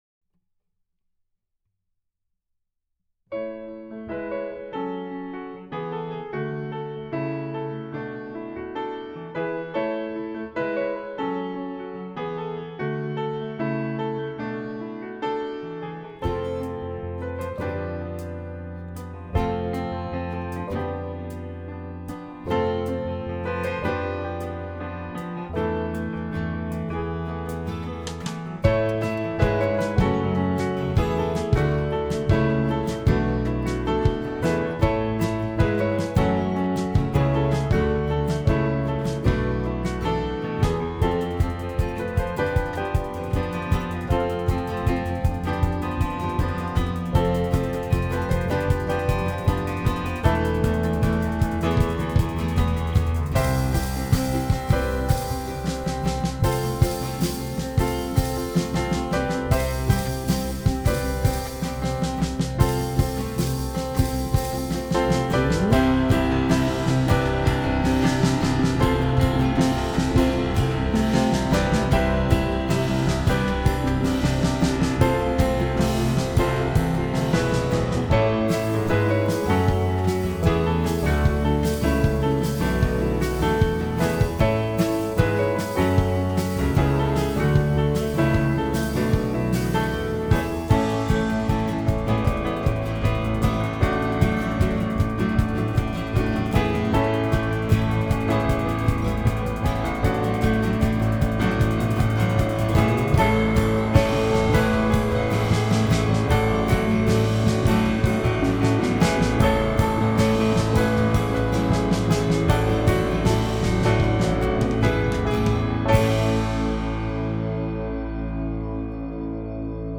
So Many Reasons To Be Happy Song-a-Day for 12-01-09 So Many Reasons To Be Happy video audio score uke part guitar part High production value, upbeat version!!!